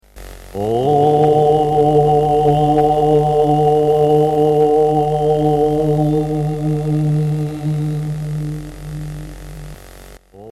Ooooooooooom
Une syllabe sacrée psalmodiée pour favoriser la méditation. 2.